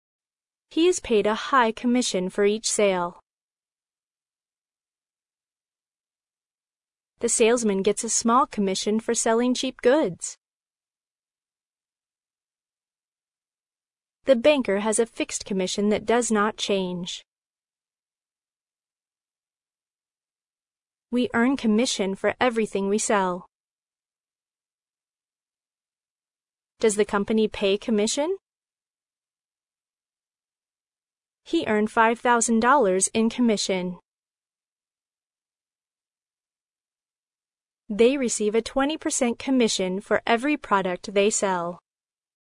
commission-pause.mp3